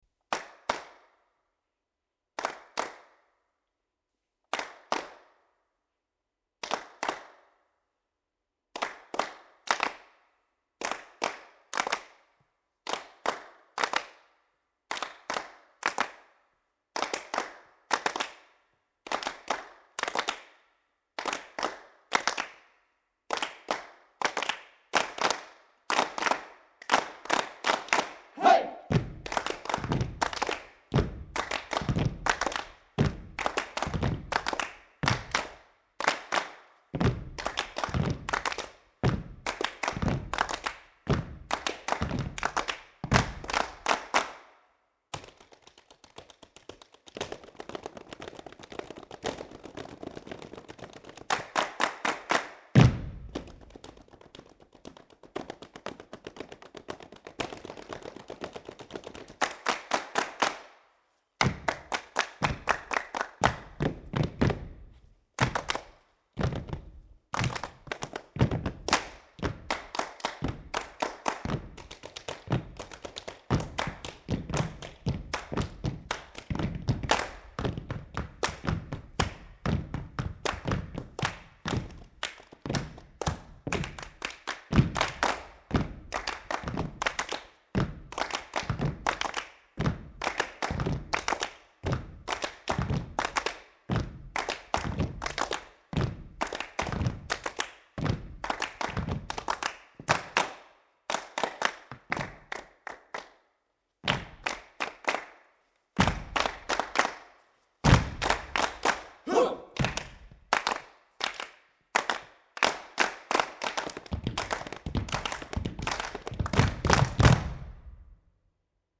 Bladmuziek voor junior percussie ensemble, entertainment.